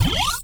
powerBlackHole.wav